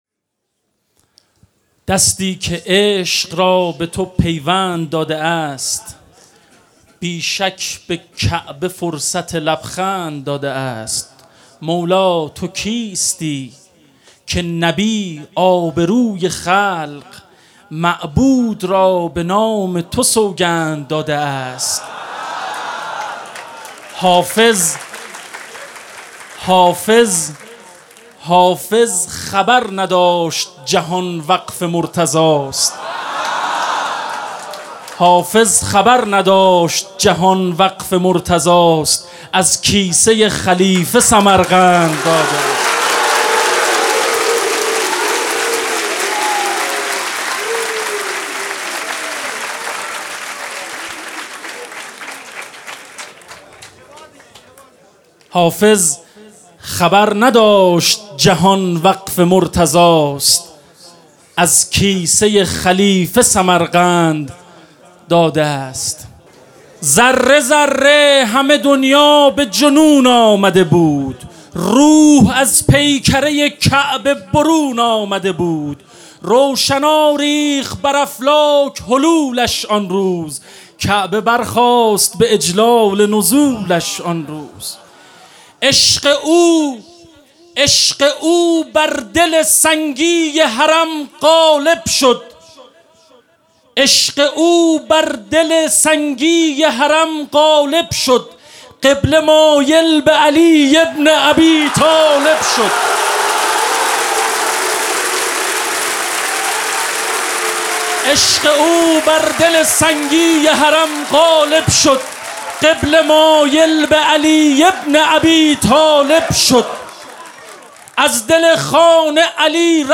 مناسبت : ولادت امیرالمومنین حضرت علی علیه‌السلام
قالب : مدح